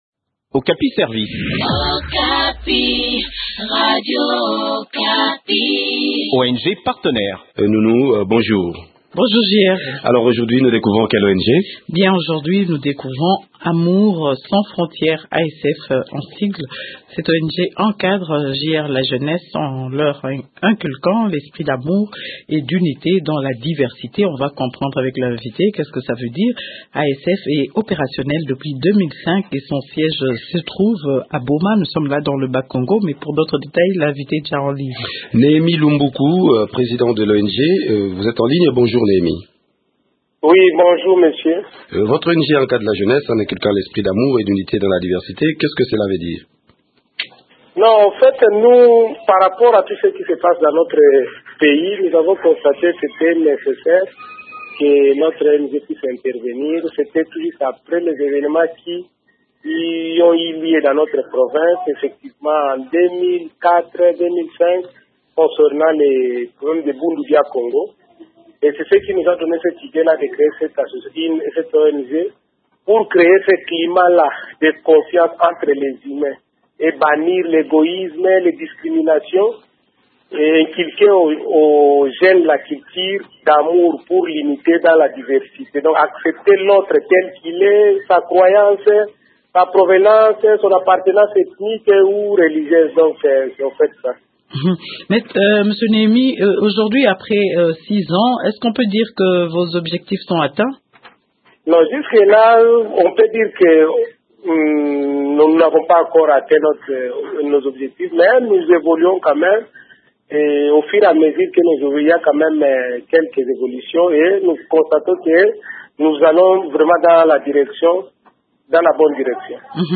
Le point des activités de cette ONG dans cet entretien